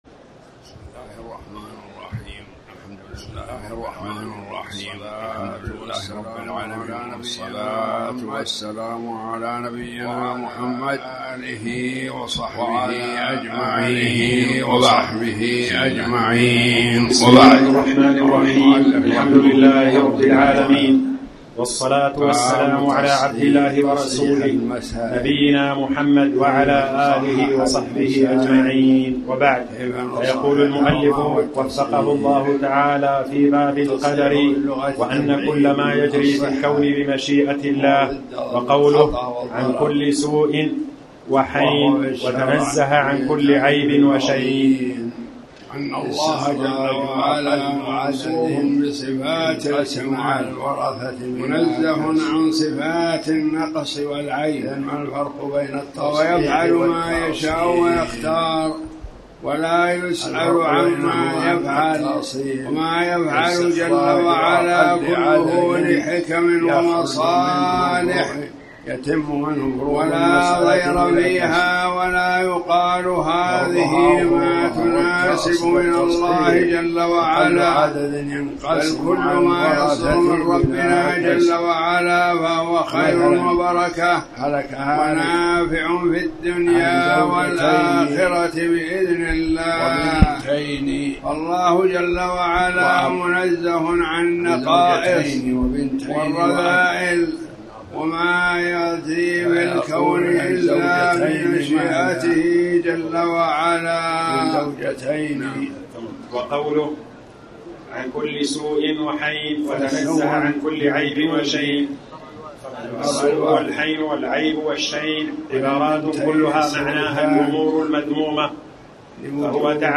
تاريخ النشر ١٥ ربيع الثاني ١٤٣٩ هـ المكان: المسجد الحرام الشيخ